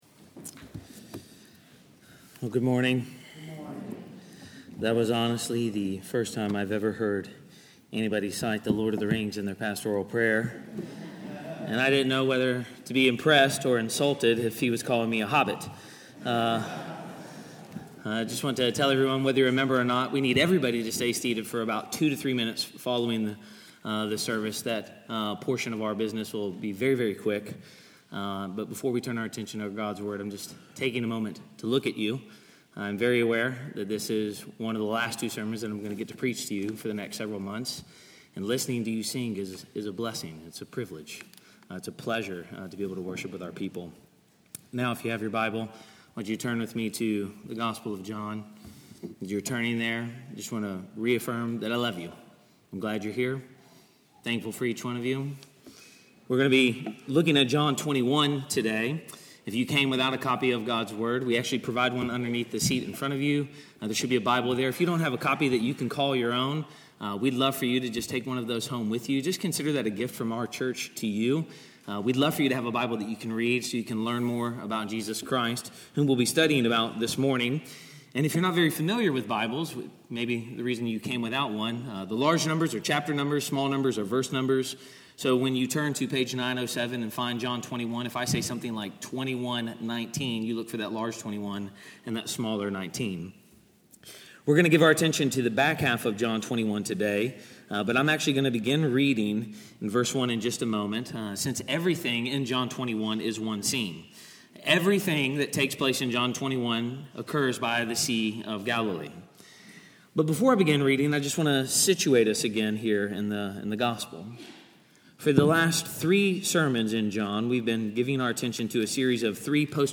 Sermon-61.mp3